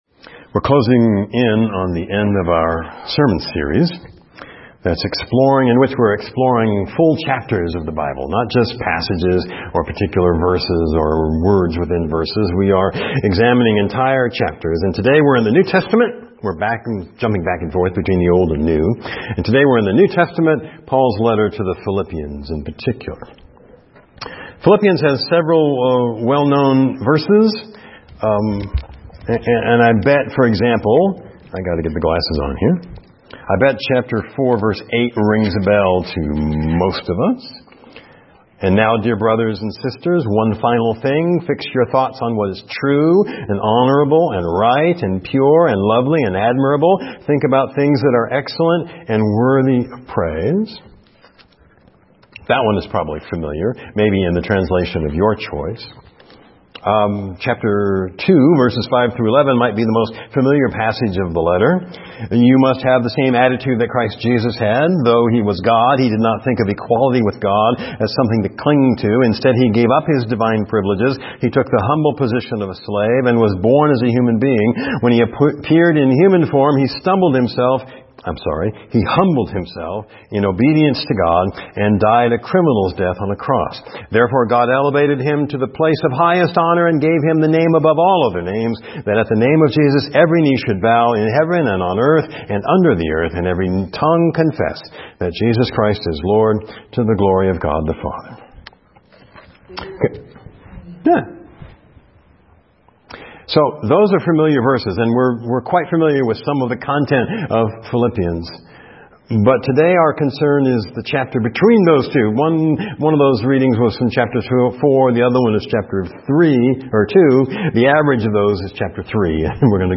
Sermon - 9-10-17.mp3